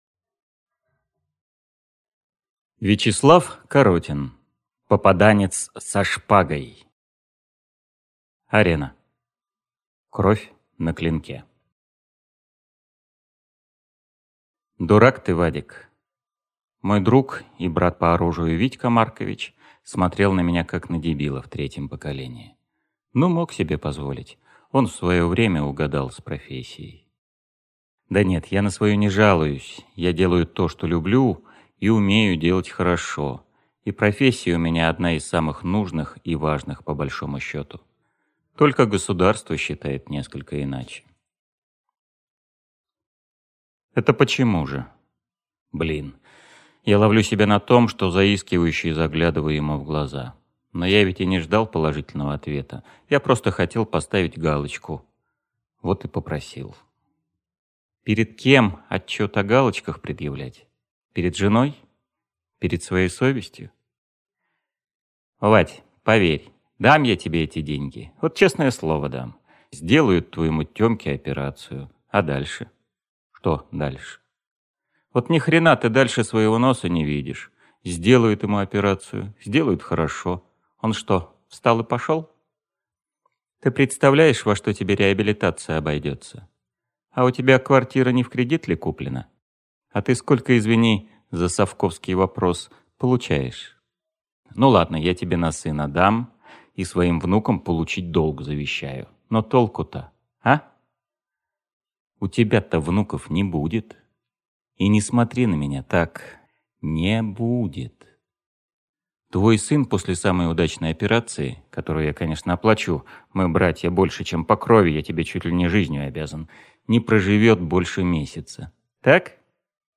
Аудиокнига Попаданец со шпагой | Библиотека аудиокниг